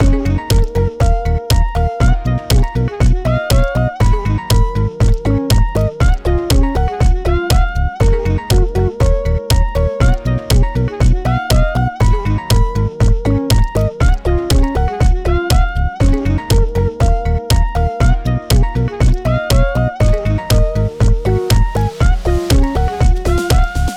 Aggressive (Yellow)
Gb Major
Funky Mid
Slime Lead
Saw Memories